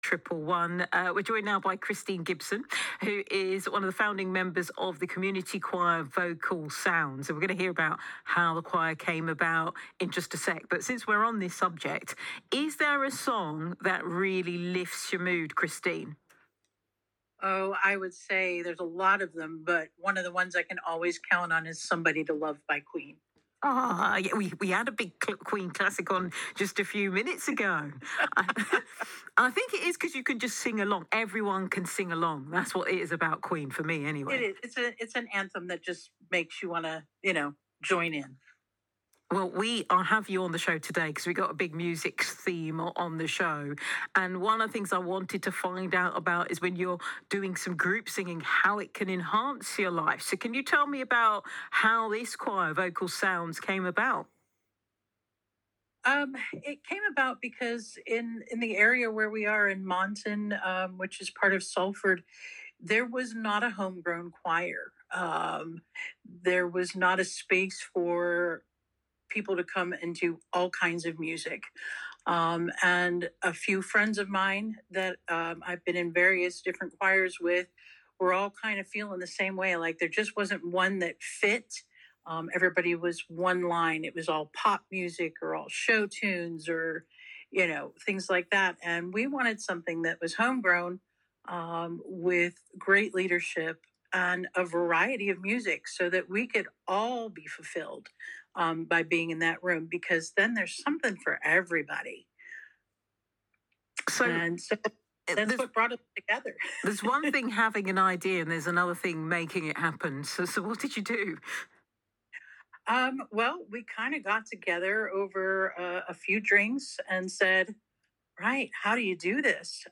BBC Radio Berkshire Interview - 7th April 2025
recorded live on 7th April 2025